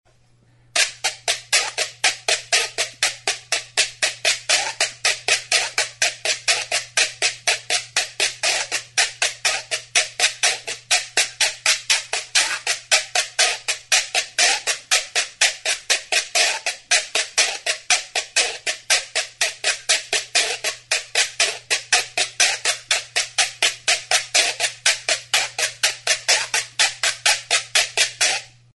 GÜIRO; RASCADOR | Soinuenea Herri Musikaren Txokoa
Idiophones -> Frottés
Enregistré avec cet instrument de musique.
Hiru hortzeko sarde batekin raskatzen da.